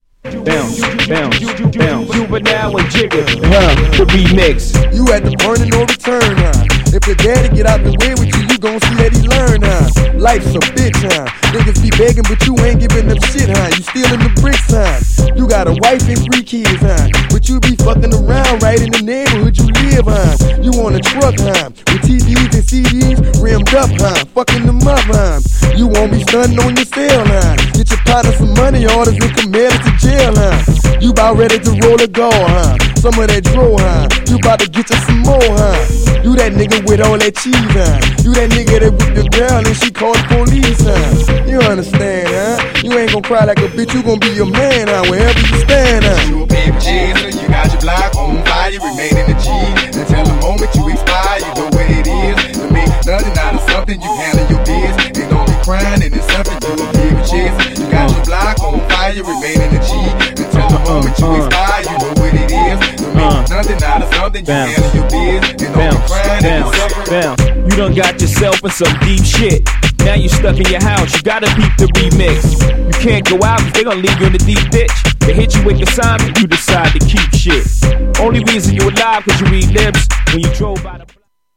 GENRE Hip Hop
BPM 86〜90BPM